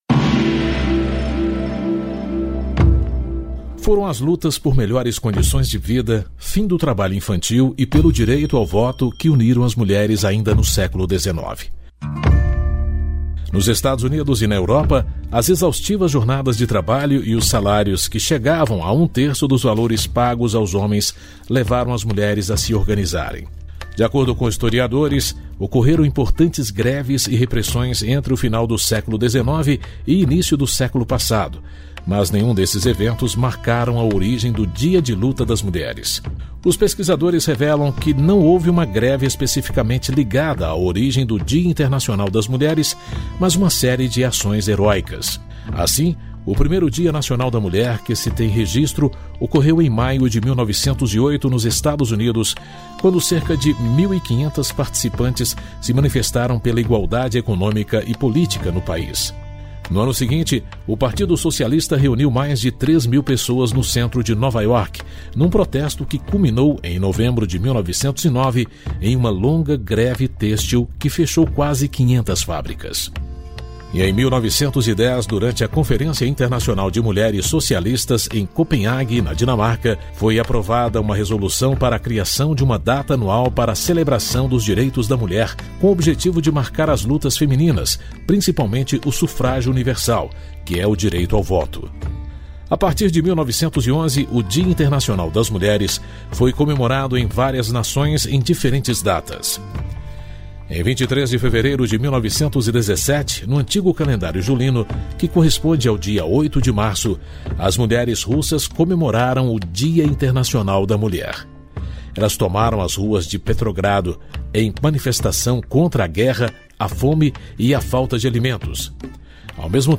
História Hoje: Programete sobre fatos históricos relacionados a cada dia do ano.